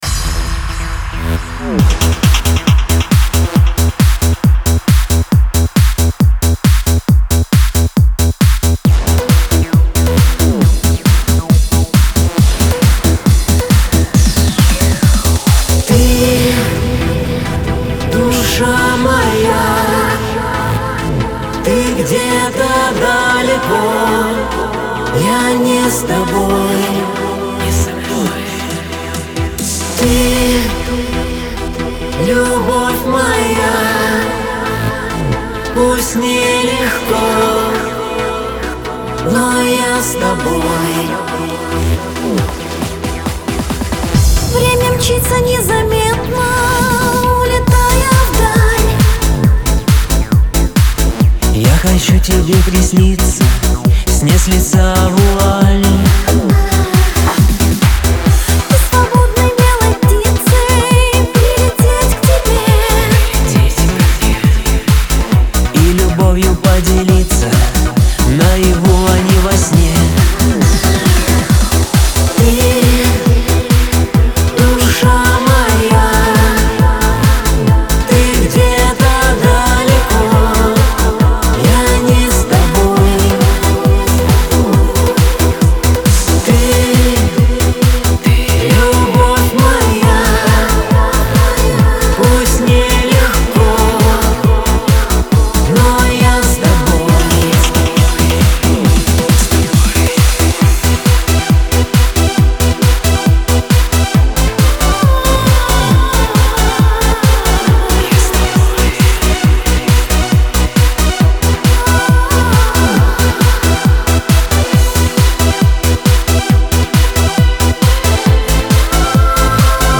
дуэт
pop
диско